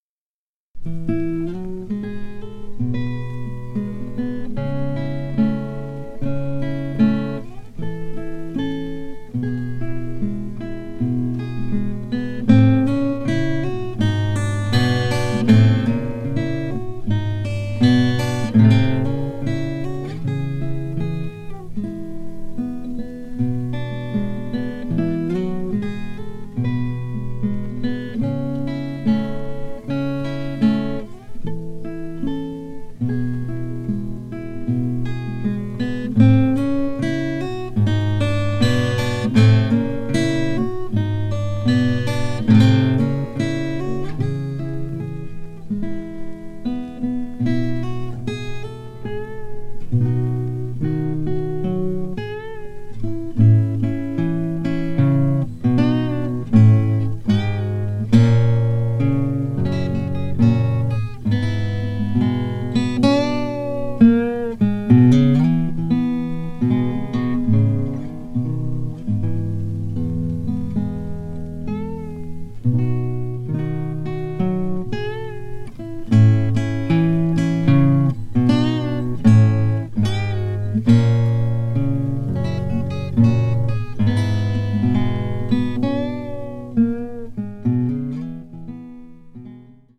Ambient Rock